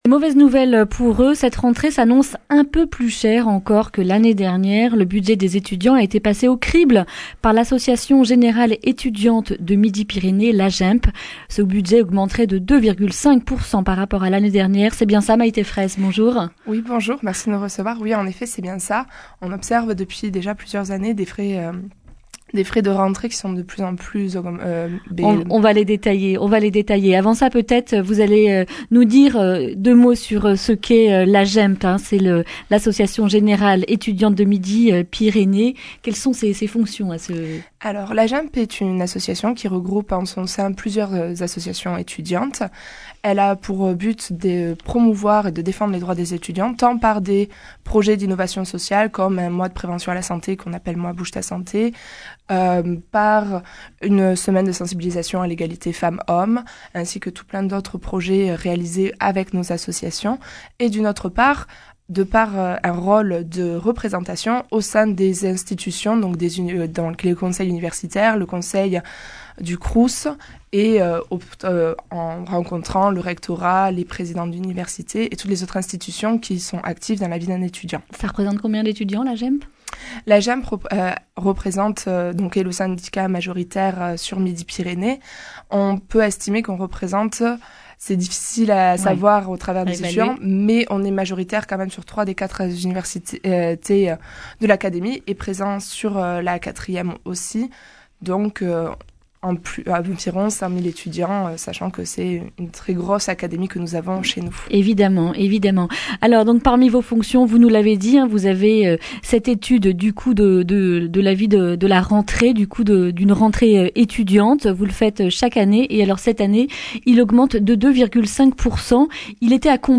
lundi 9 septembre 2019 Le grand entretien Durée 10 min